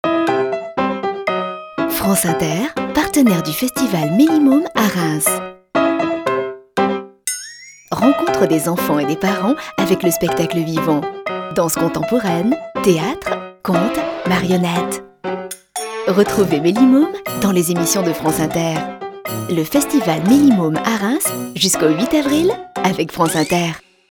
Voix jeune et fraiche mais pouvant être aussi plus mature ou plus sensuelle.
Sprechprobe: Sonstiges (Muttersprache):